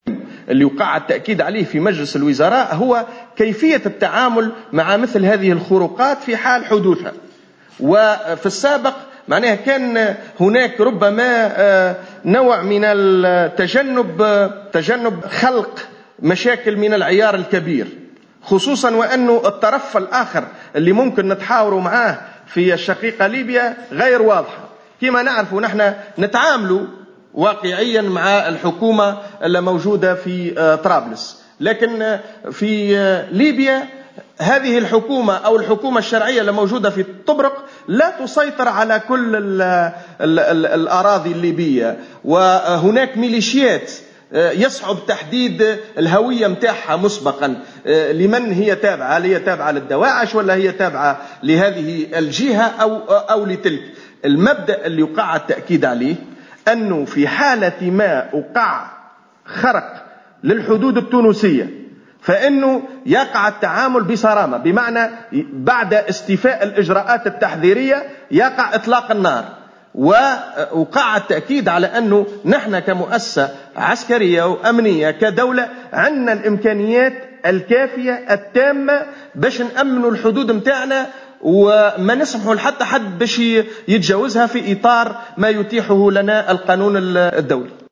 قال خالد شوكات الناطق الرسمي باسم رئاسة الحكومة في تصريح للجوهرة أف أم على هامش مجلس وزاري عقد اليوم الأربعاء 09 مارس 2016 بقصر الحكومة بالقصبة إن تونس ستتعامل بصرامة على الحدود مع ليبيا.